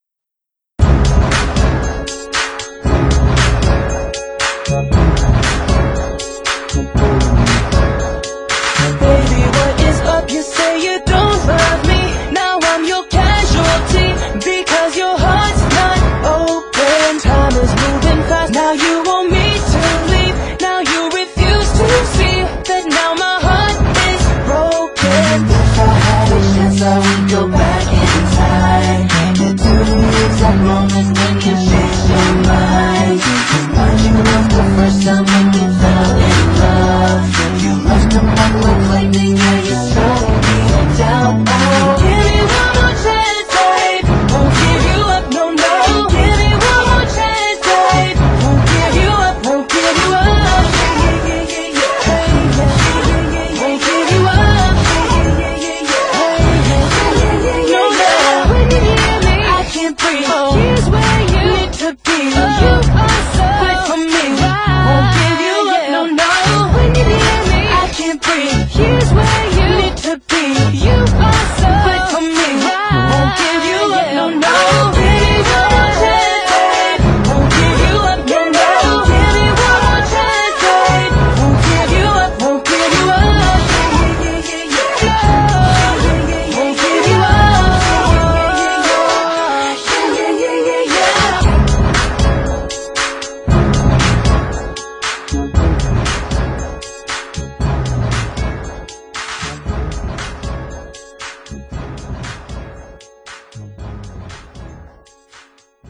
BPM116
Audio QualityPerfect (Low Quality)